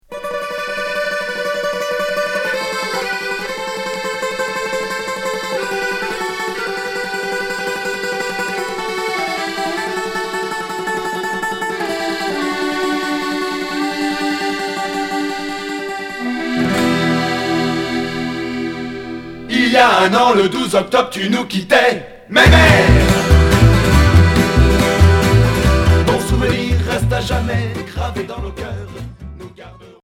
Folk Rock Cinquième 45t retour à l'accueil